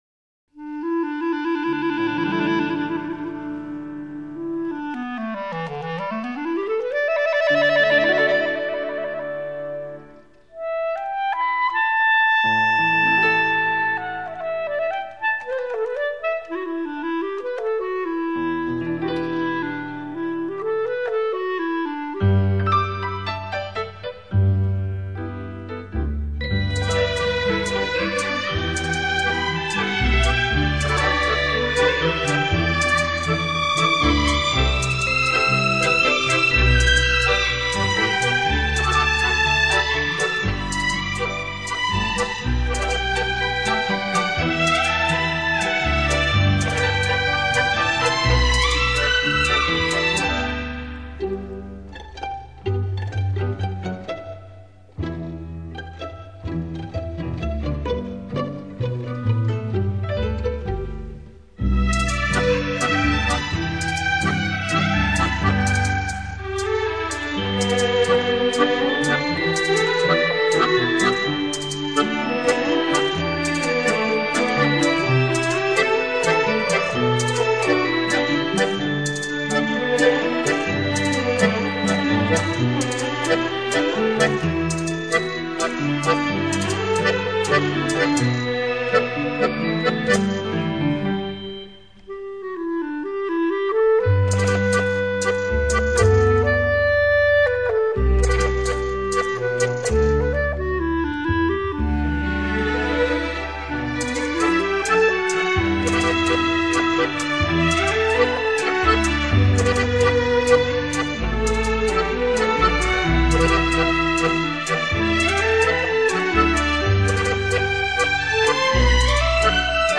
江南乐曲经典 精心编曲 用心制作